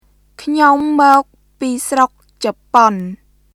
[クニョム・モーク・ピー・スロック・チャポン　kʰɲom mɔ̀ːk piː srok capon]